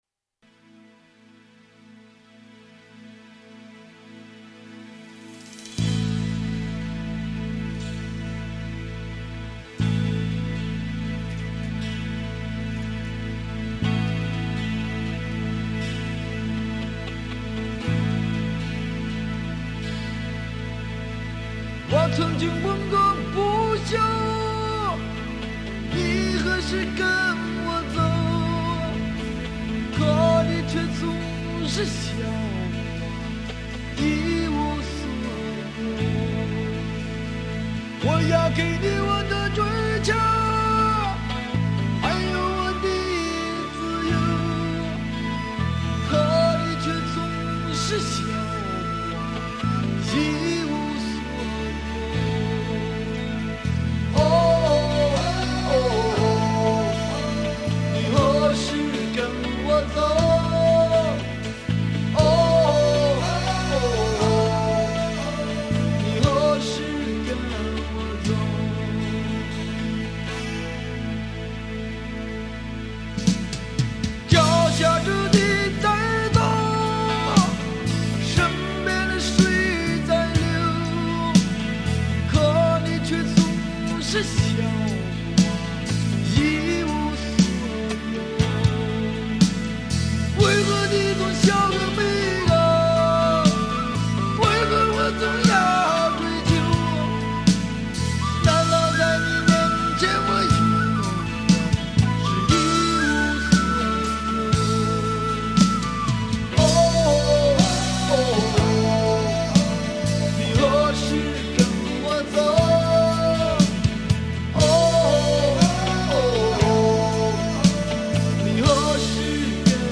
他是用喉咙唱出了诗人的自觉。